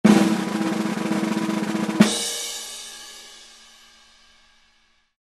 Полный звук тарелки